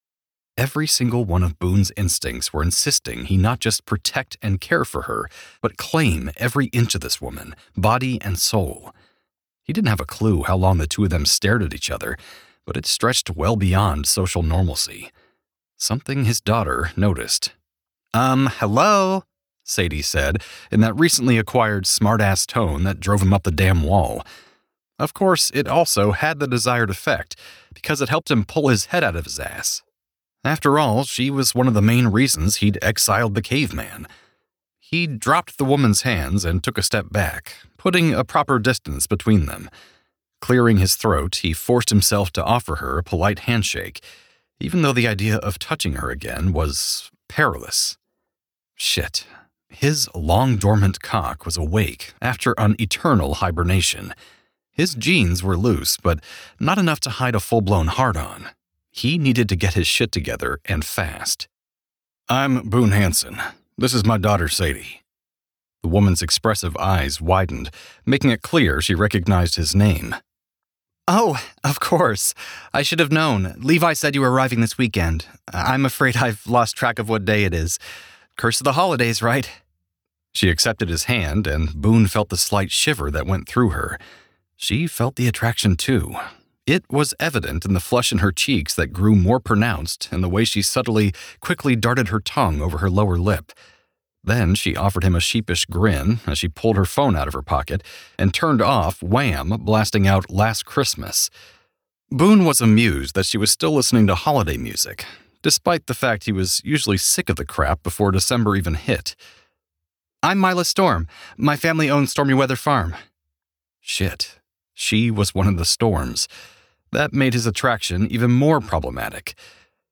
21_CaughtInAStorm_MaleSample.mp3